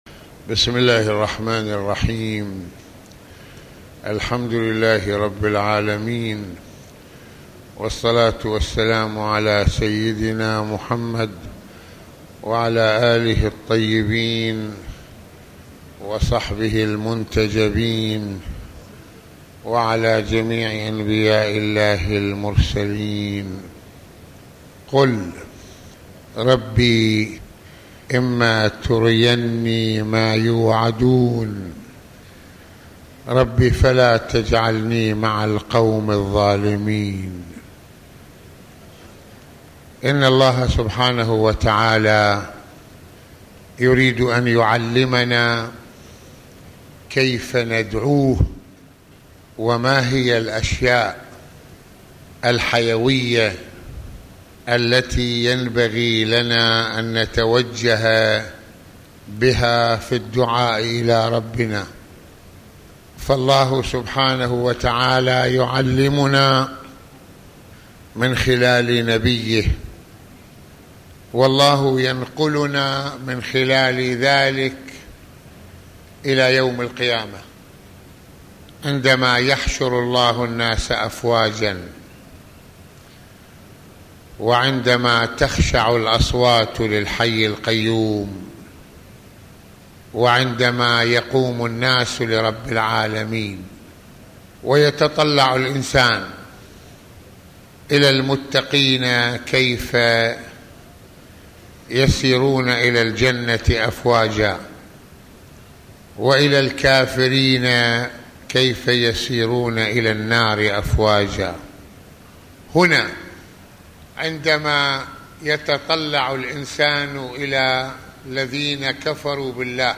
ملفات وروابط - المناسبة : موعظة ليلة الجمعة المكان : مسجد الإمامين الحسنين (ع) المدة : 33د | 26ث المواضيع : ظلم النفس وجزائه يوم القيامة - النبي(ص) والدعوة بالتي هي احسن - دور الاستعاذة بالله من الشيطان